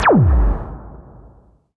beam-turret-fire.wav